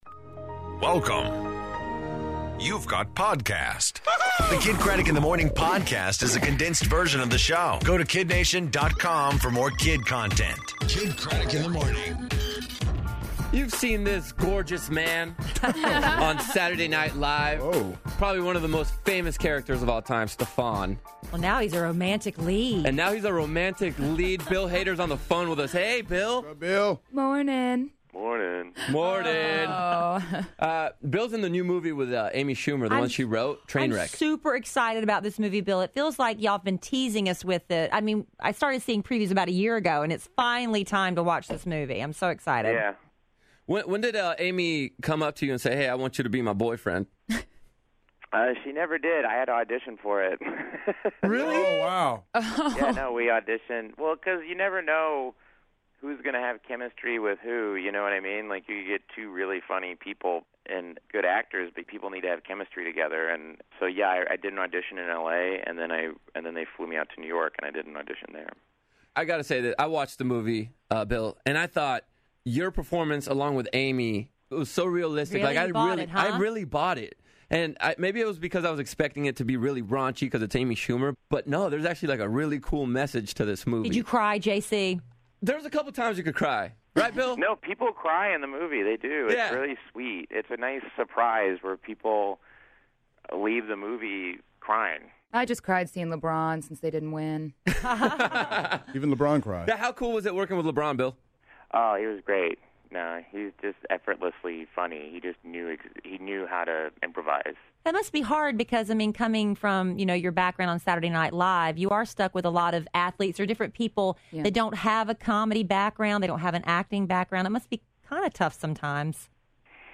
Getting Ready for K2M3, MKTO In Studio, And Andy Grammer On The Phone